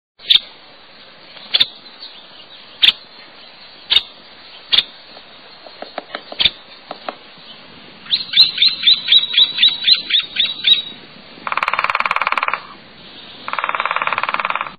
Bird Sound
Call note a double "pitik."
Nuttall'sWoodpecker.mp3